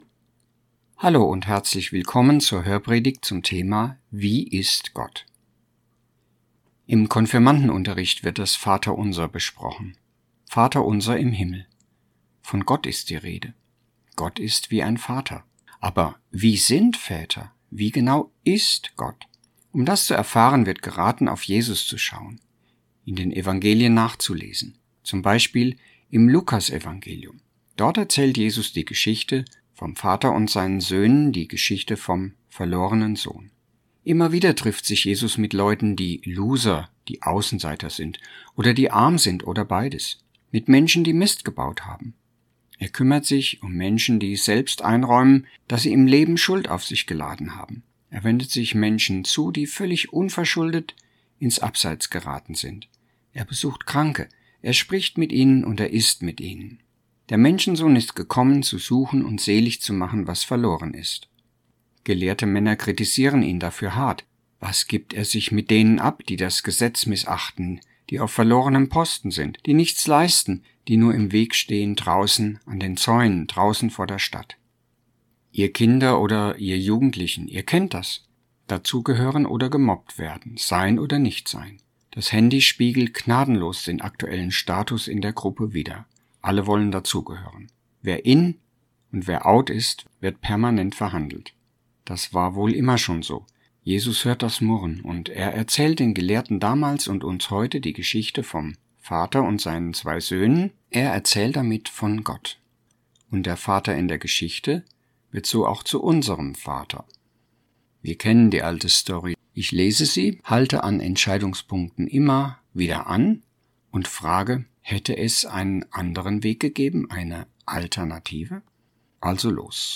Hörpredigt-ZV-August_2025.mp3